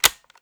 BUTTON_05.wav